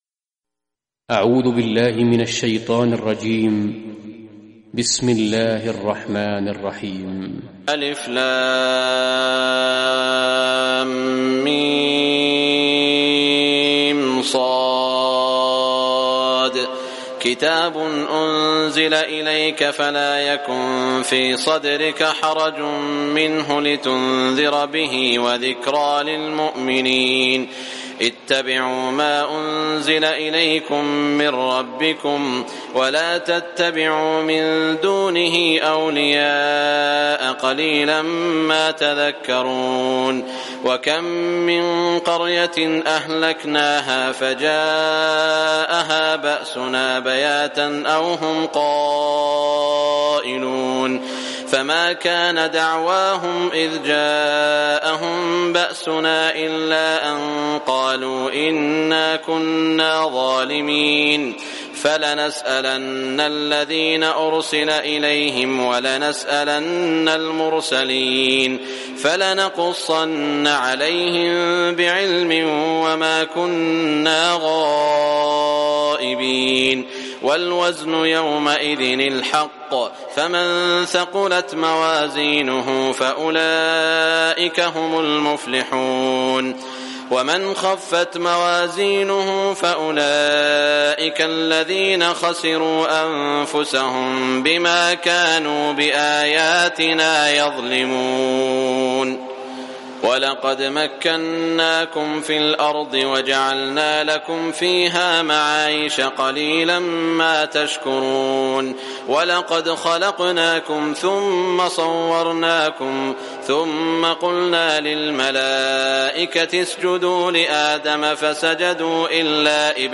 Surah Al-Araf Recitation by Sheikh Shuraim
Surah Al-Araf, listen or play online mp3 tilawat / recitation in Arabic in the voice of Sheikh Saud Al Shuraim.